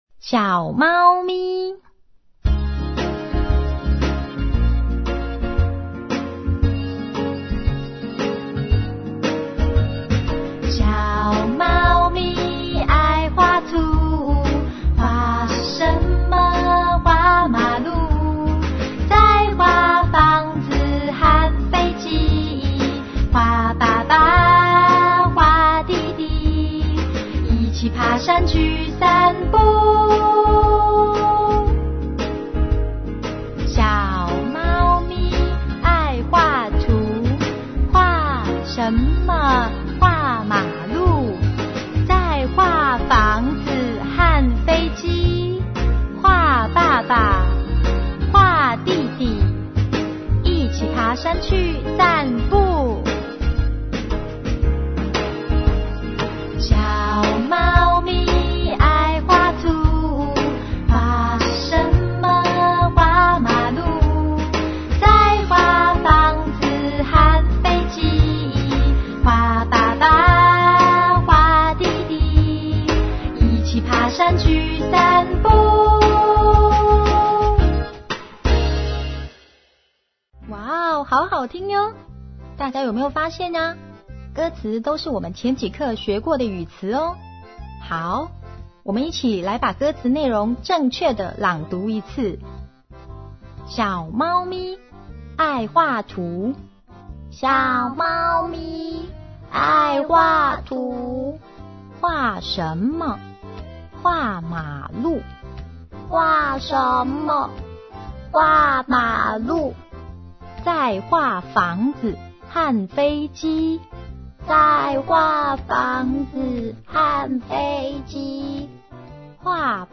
◎CD裡附有好聽的「拼音兒歌」